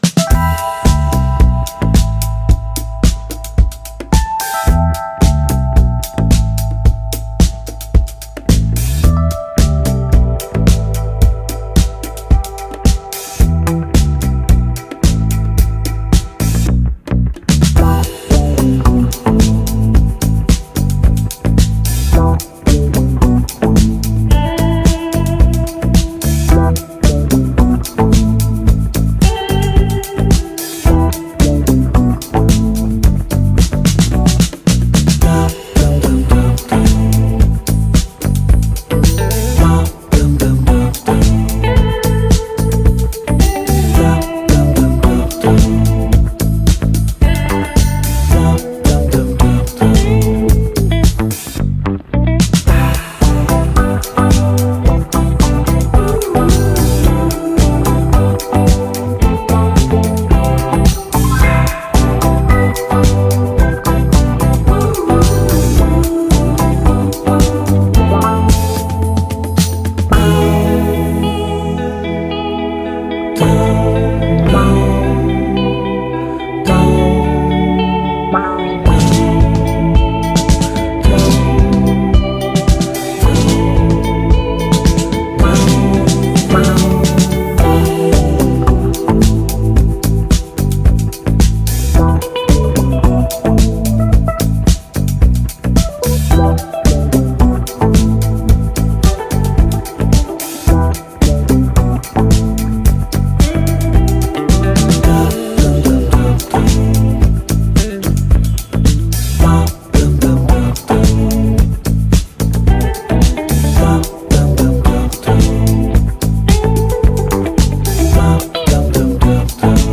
background.ogg